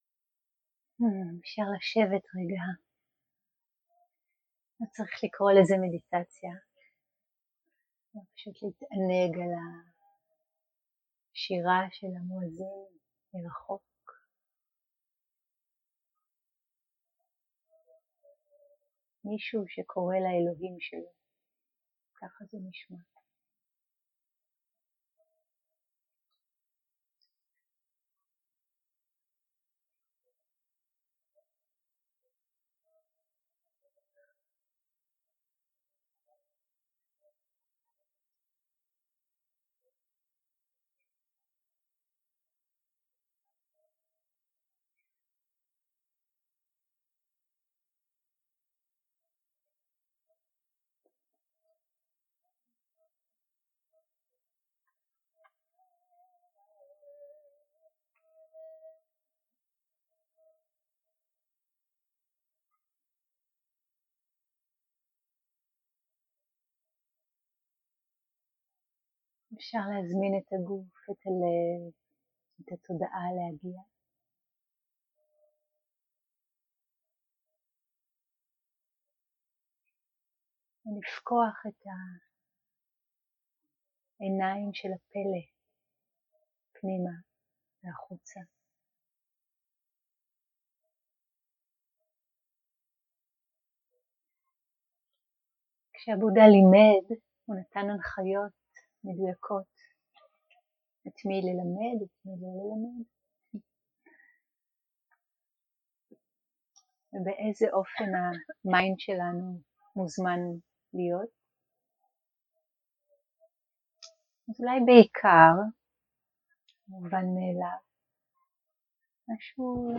שיחות דהרמה שפת ההקלטה